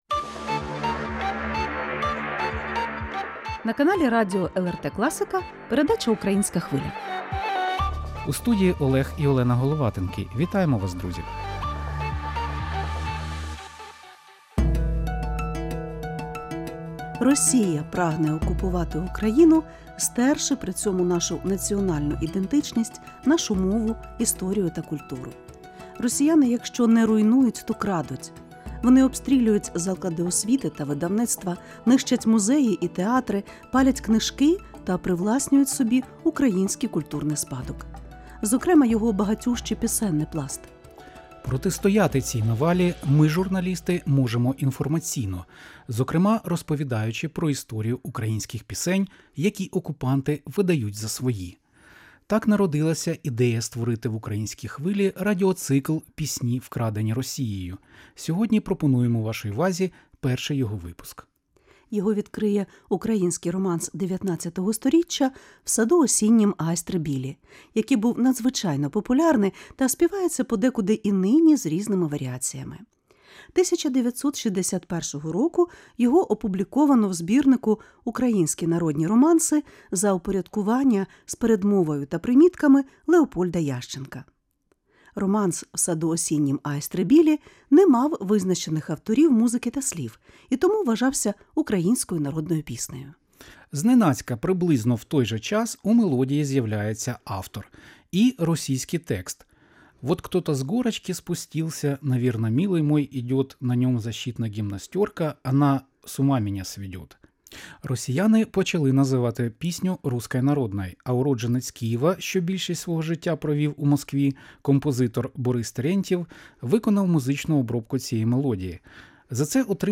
Подаємо архівні матеріали, свідчення дослідників, а також думки українських музикантів, які повертають цим пісням первісний зміст і звучання.